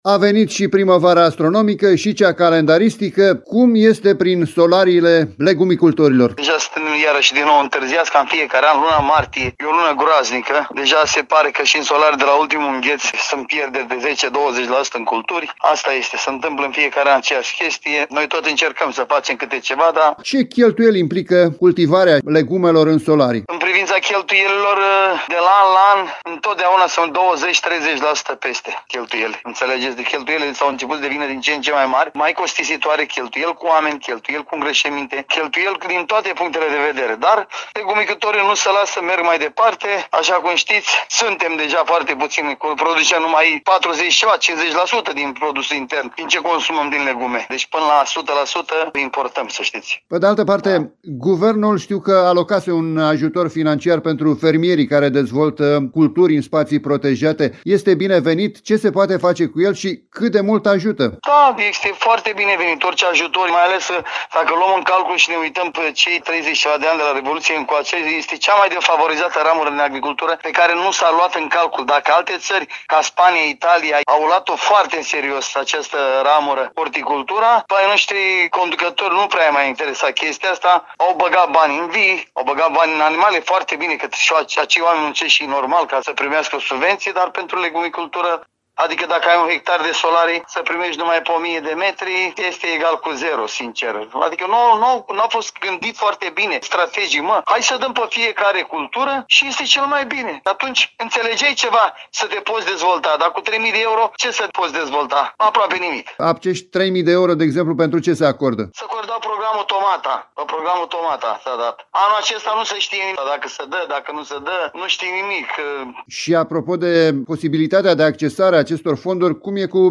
un legumicultor din zona Topraisar.